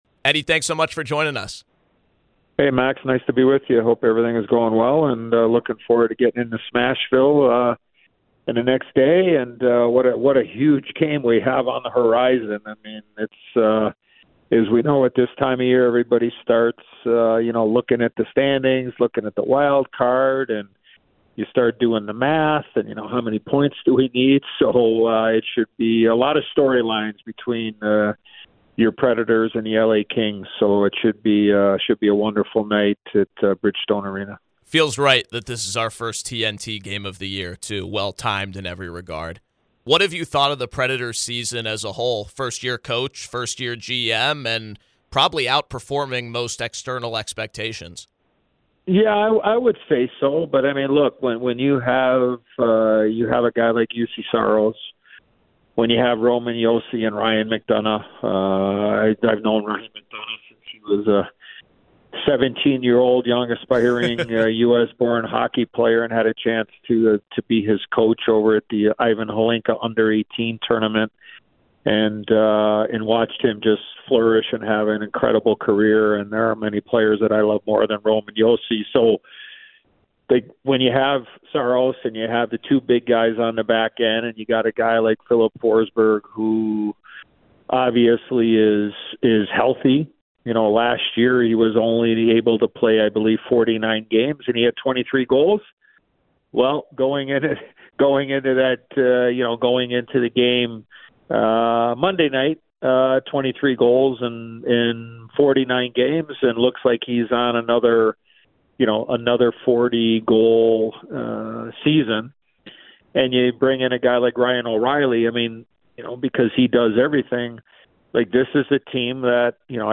Featured Conversation